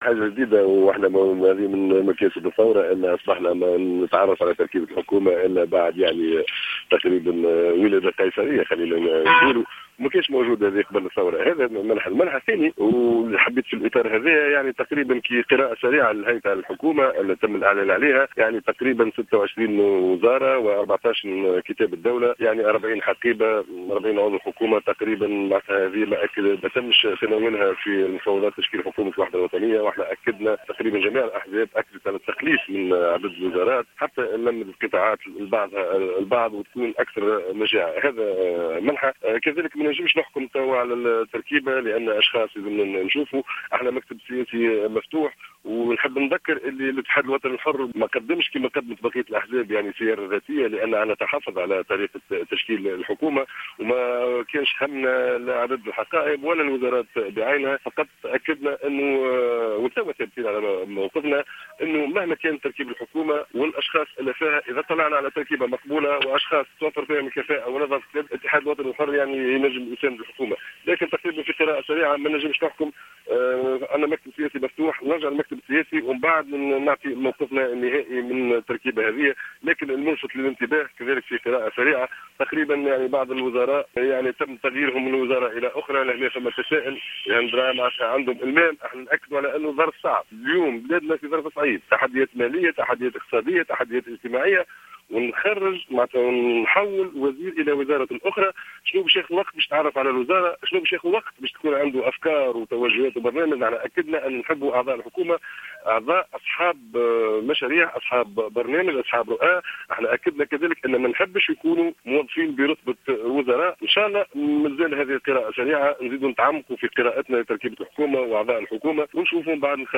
واعتبر في تصريح لـ "الجوهرة اف أم" أن عدد الحقائب الوزارية بهذه الحكومة مرتفع حيث بلغ عددها 40 حقيبة وزارية تتوزع بين وزارة وكتابة دولة.